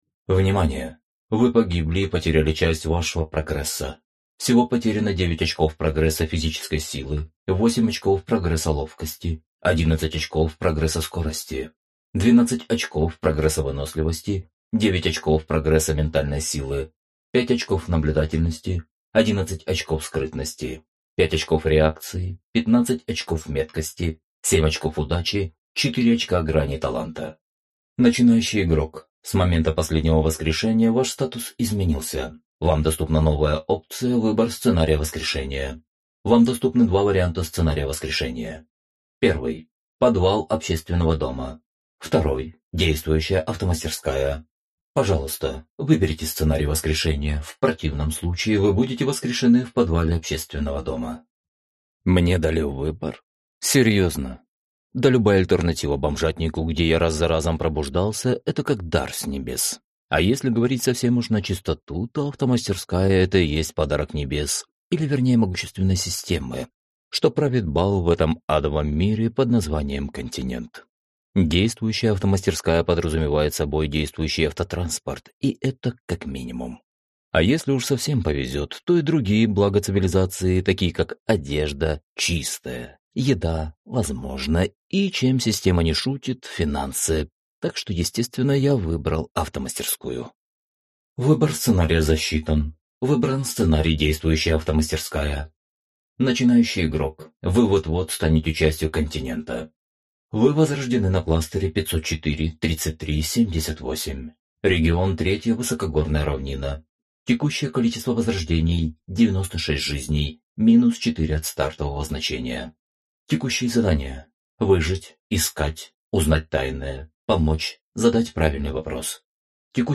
Аудиокнига S-T-I-K-S. Долгая дорога в стаб. Книга 2. Фагоцит | Библиотека аудиокниг